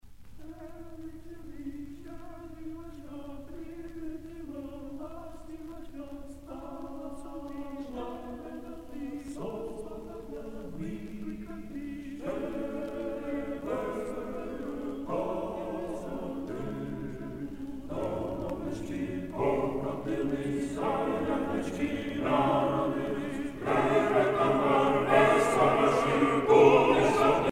circonstance : dévotion, religion
Pièce musicale éditée